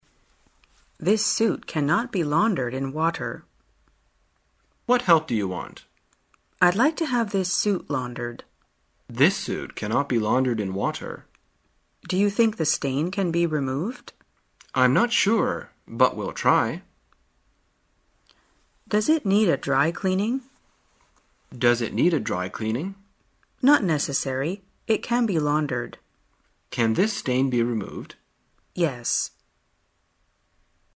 在线英语听力室生活口语天天说 第139期:怎样谈论洗涤方法的听力文件下载,《生活口语天天说》栏目将日常生活中最常用到的口语句型进行收集和重点讲解。真人发音配字幕帮助英语爱好者们练习听力并进行口语跟读。